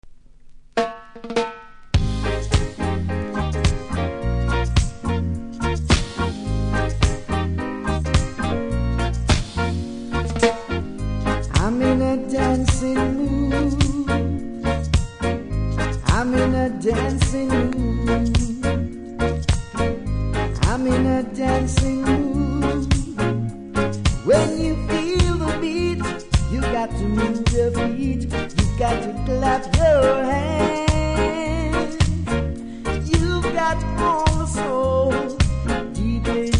キズもノイズも少なく良好盤です。